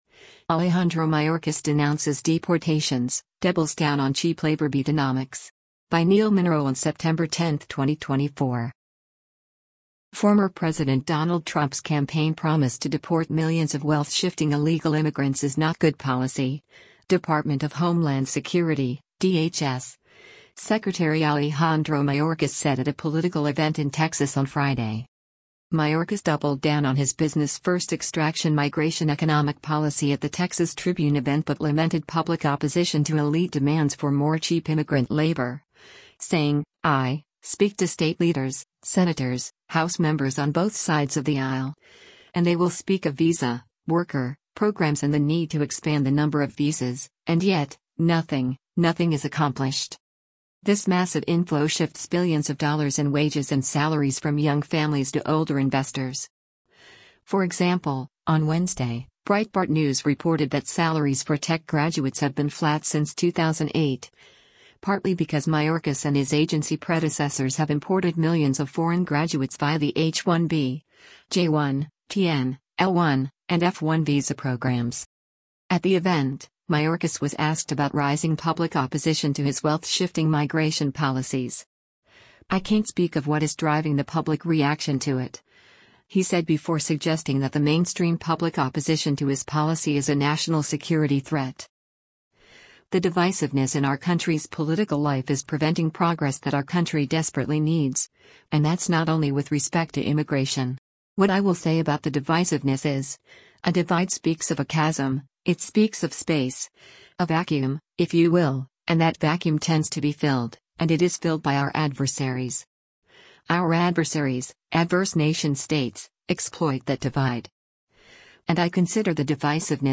Mayorkas FEMA Interview
Former President Donald Trump’s campaign promise to deport millions of wealth-shifting illegal immigrants “is not good policy,” Department of Homeland Security (DHS) Secretary Alejandro Mayorkas said at a political event in Texas on Friday.